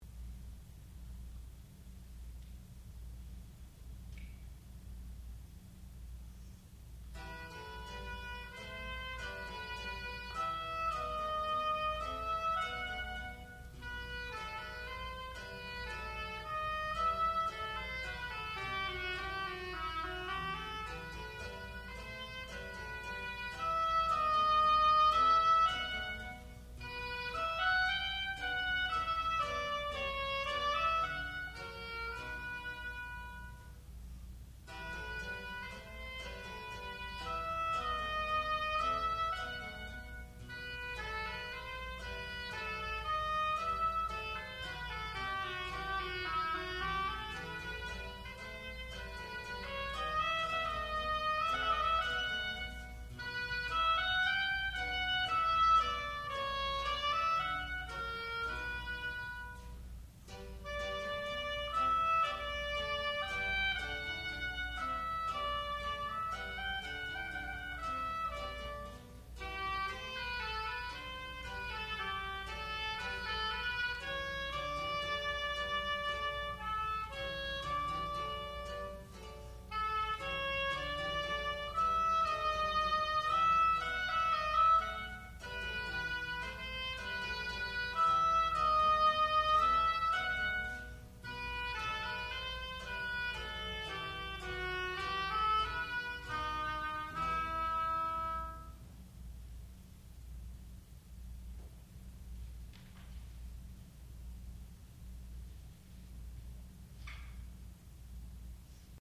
classical music
oboe
harpsichord
Graduate Recital